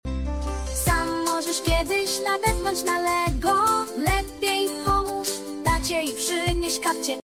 Piosenka dla dzieci